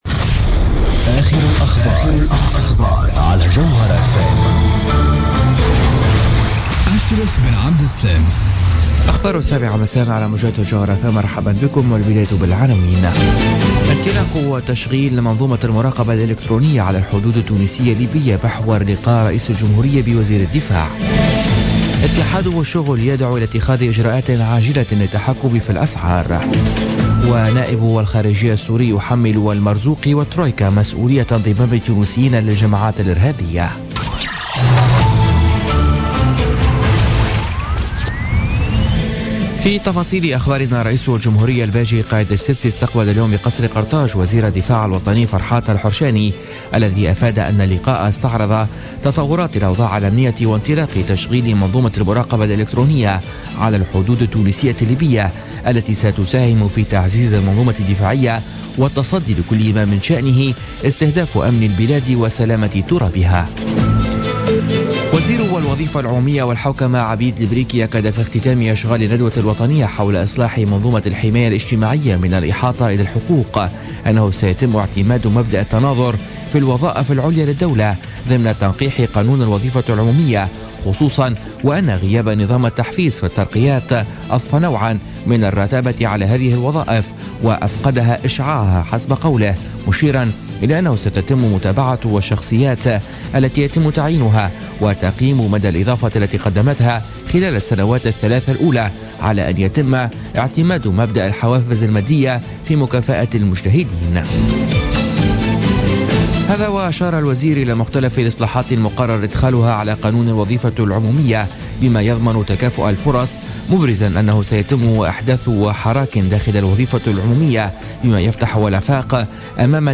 نشرة أخبار السابعة مساء ليوم الاربعاء غرة فيفري 2017